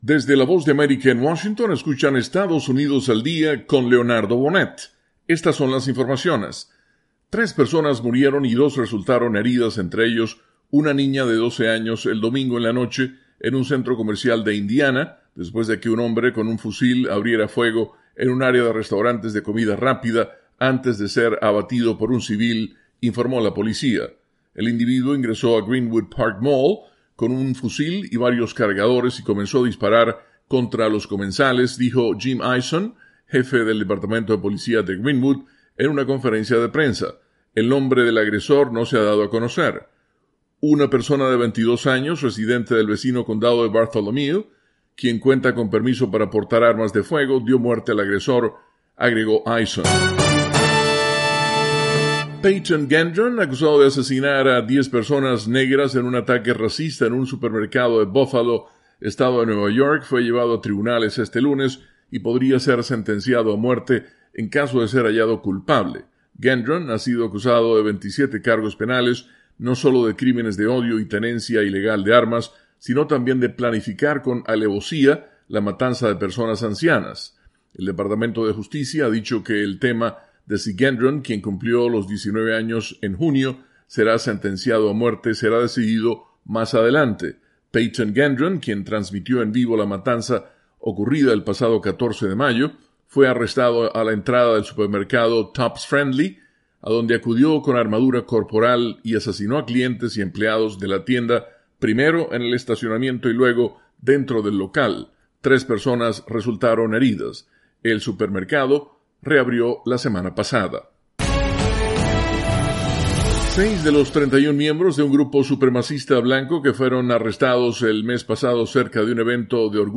Informativo de 3 minutos que transmite la Voz de América, desde Washington